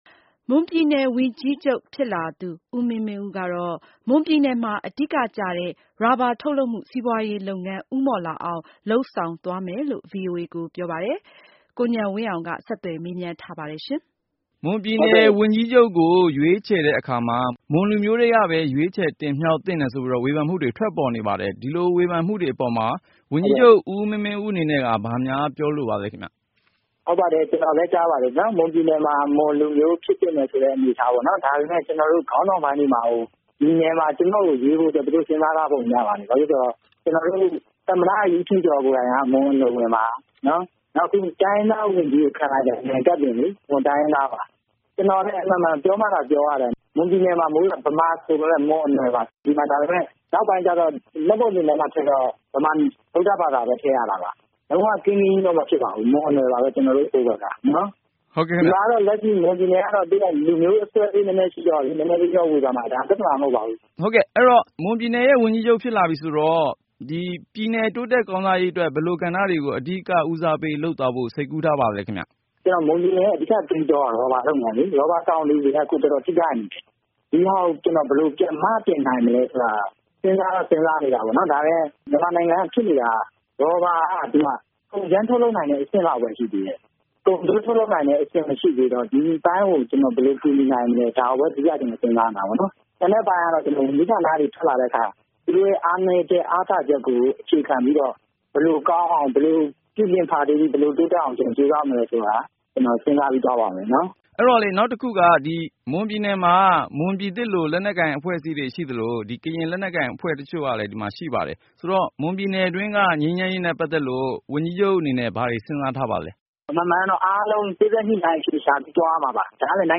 မွန်ပြည်နယ်ဝန်ကြီးချုပ်သစ်နဲ့ ဆက်သွယ်မေးမြန်းခန်း